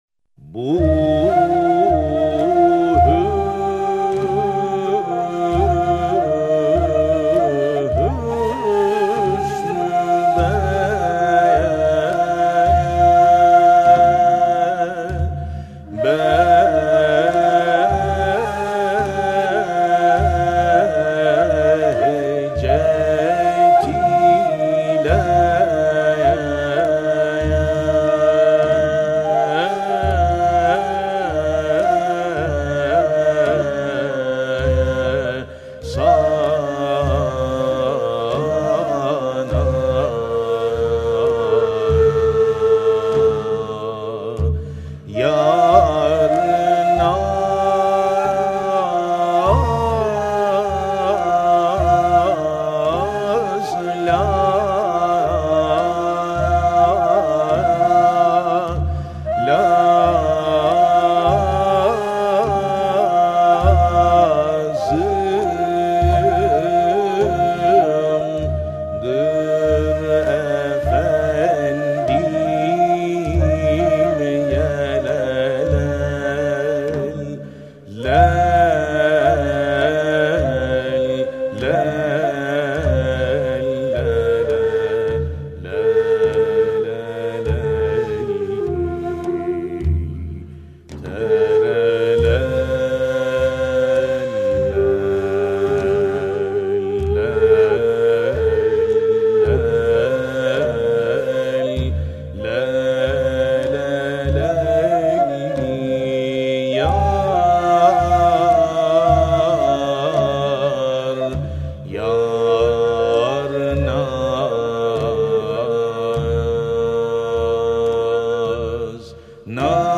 Makam: Sultani Irak Form: Beste Usûl: Zincir